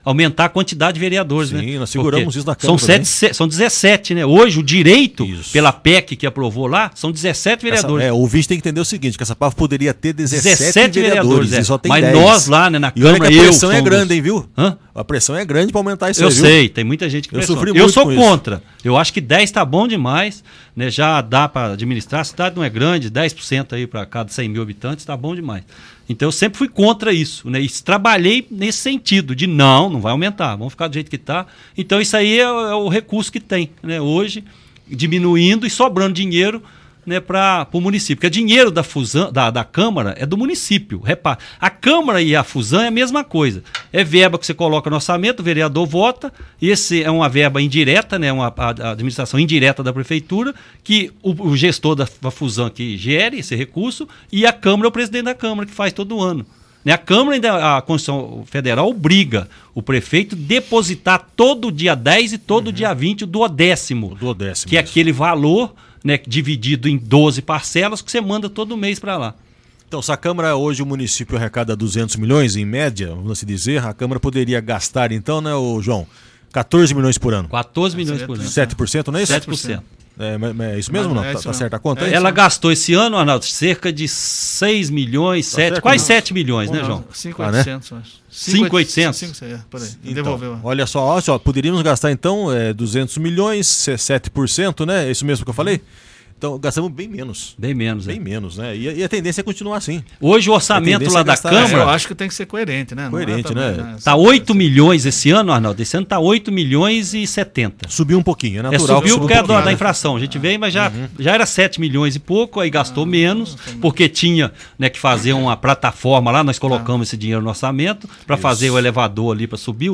Veja a entrevista do ex-prefeito Rinco, na Rádio Santo Antonio!!!